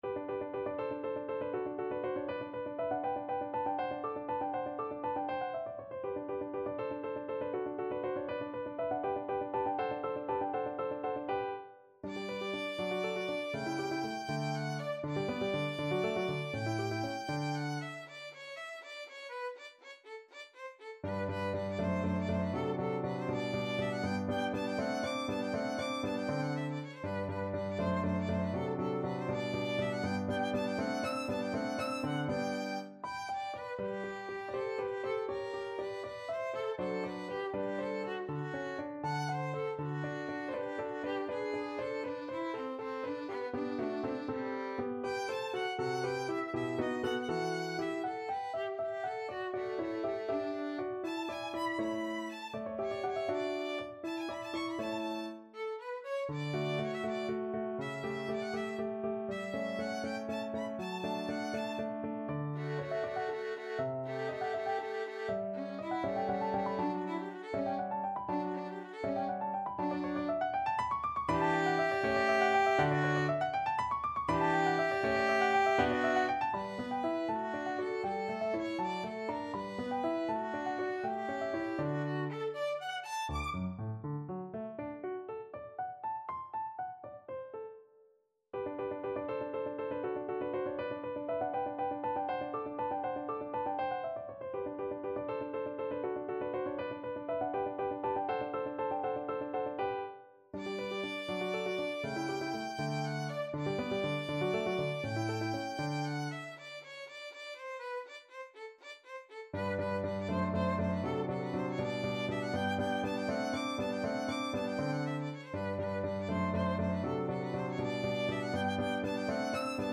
Violin
G major (Sounding Pitch) (View more G major Music for Violin )
6/8 (View more 6/8 Music)
~ = 100 Allegro (View more music marked Allegro)
Classical (View more Classical Violin Music)
rondo-for-violin-and-piano-woo-41.mp3